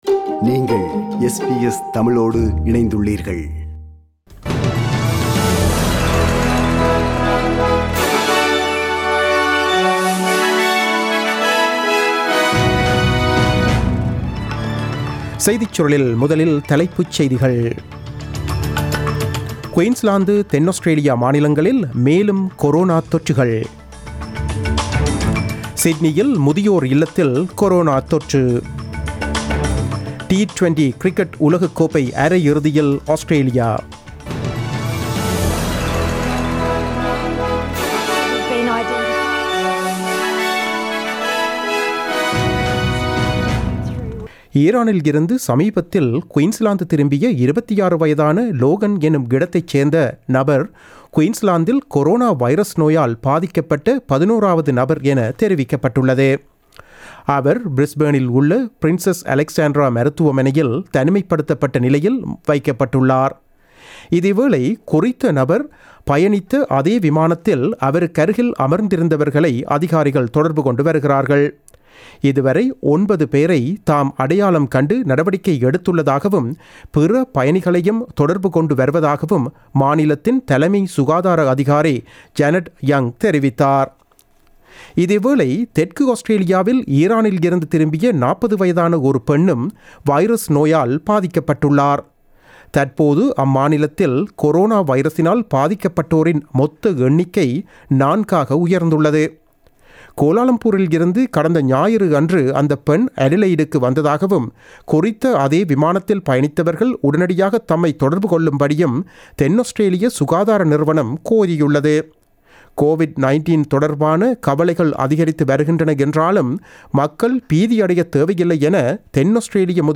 நமது SBS தமிழ் ஒலிபரப்பில் இன்று புதன்கிழமை (04 March 2020) இரவு 8 மணிக்கு ஒலித்த ஆஸ்திரேலியா குறித்த செய்திகள்.